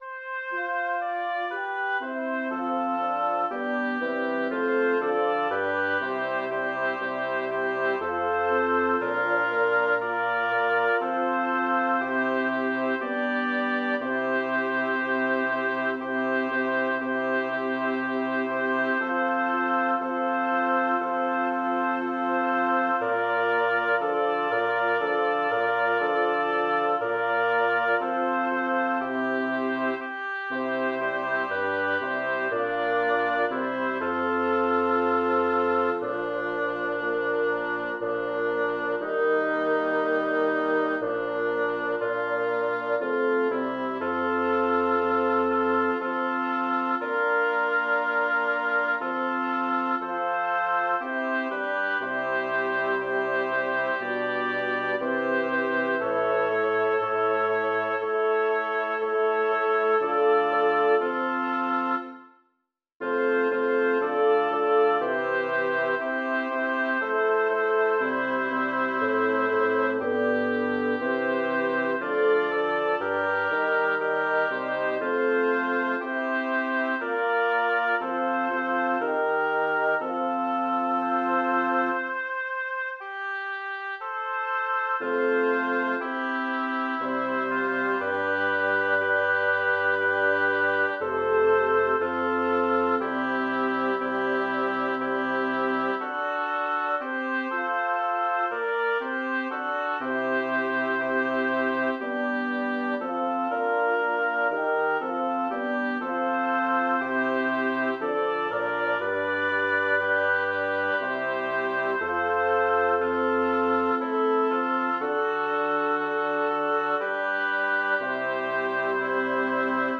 Title: Sequimini o socii Composer: Jacob Meiland Lyricist: Number of voices: 5vv Voicing: SSATB Genre: Secular, Partsong
Languages: Latin, German Instruments: A cappella